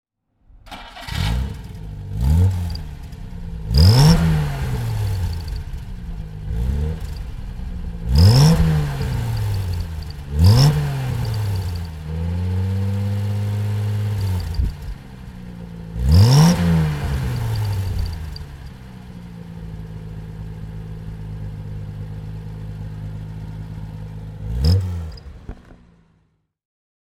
Jaguar E Type Coupé (1965) - Starten und Leerlaufgeräusch
Jaguar_E_Type_1965.mp3